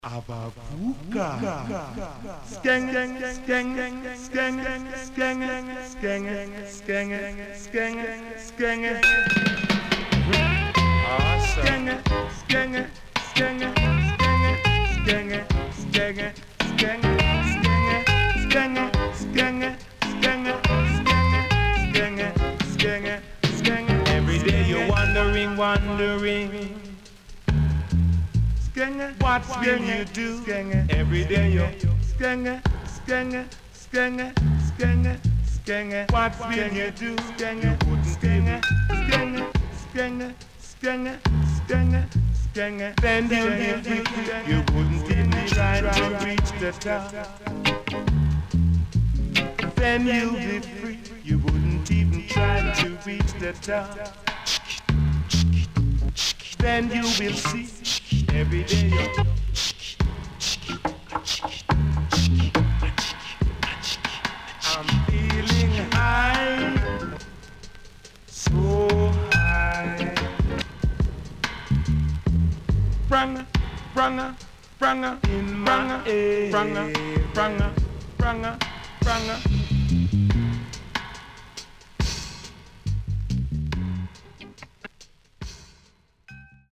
Foundation & Dubwise.Pressnoise